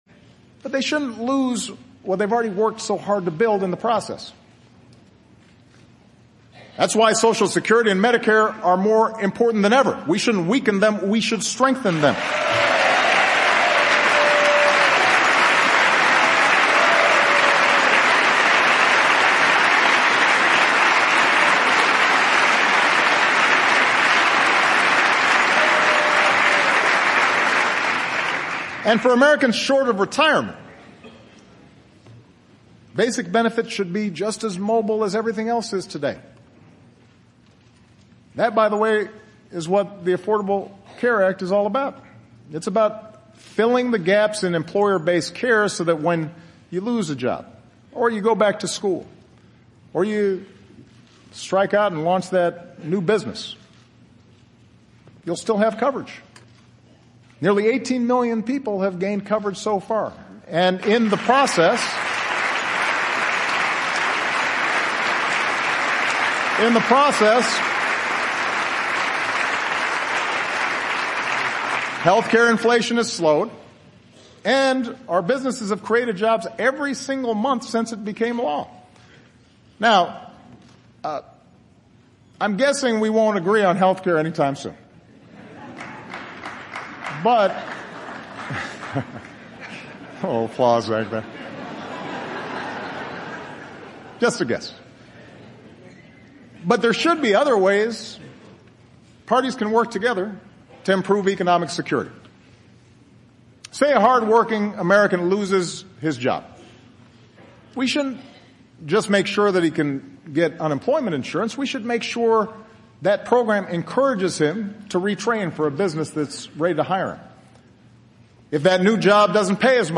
欧美名人演讲 第26期:奥巴马最后一次国情咨文(7) 听力文件下载—在线英语听力室